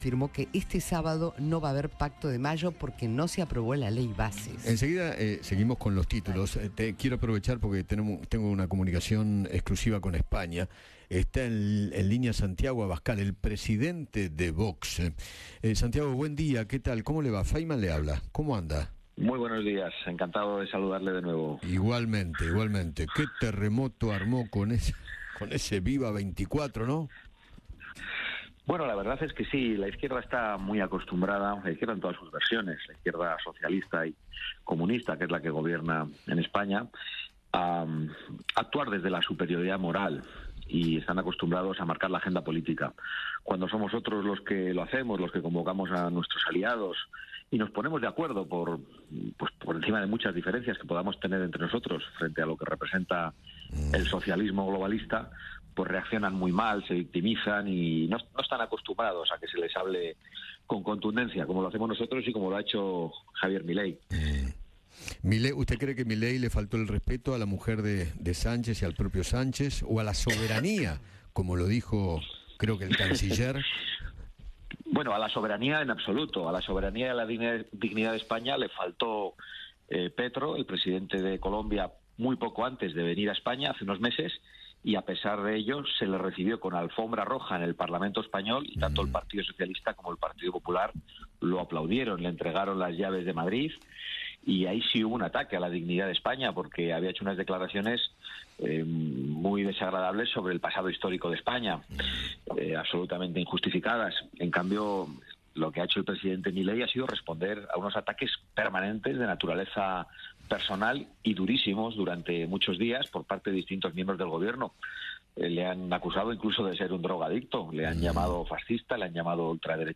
Santiago Abascal, presidente de Vox, dialogó con Eduardo Feinmann y defendió la posición de Javier Milei frente a Pedro Sánchez.